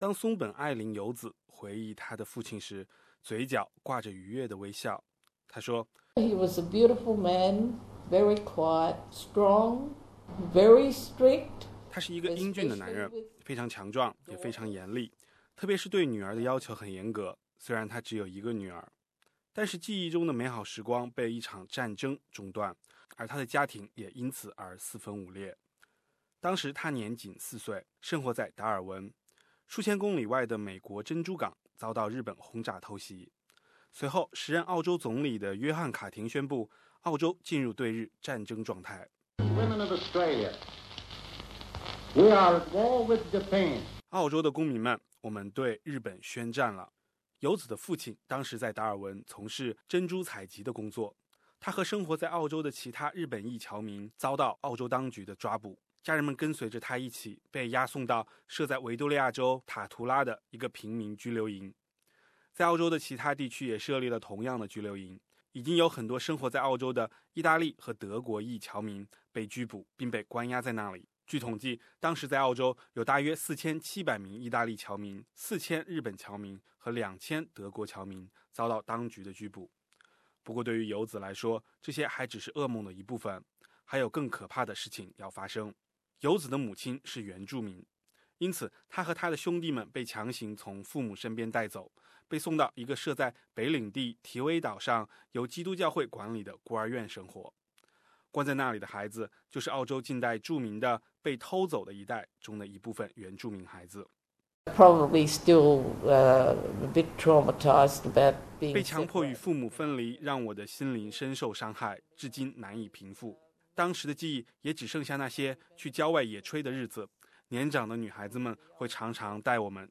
数名在第二次世界大战期间被关押在那些拘留营的幸存者，讲述了他们的故事，也揭示了这段鲜为人知的澳洲黑色记忆。